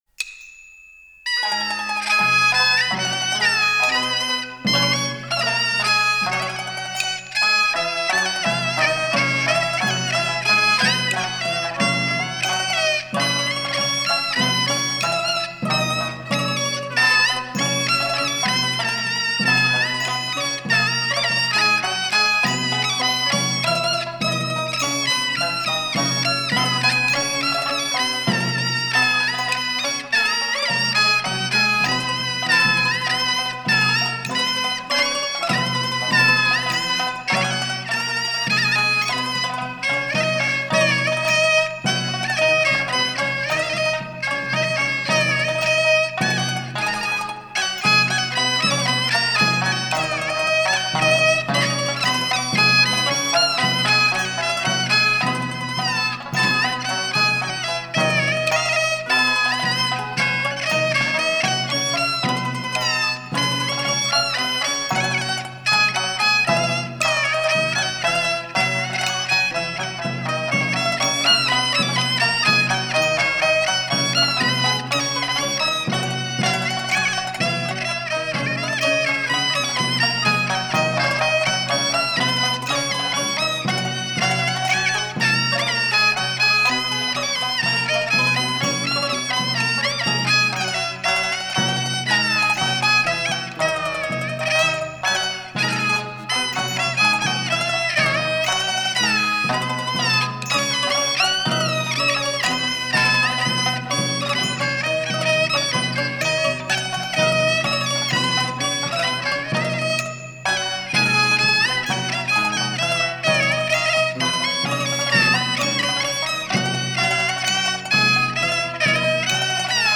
0029-京胡名曲哪吒令.mp3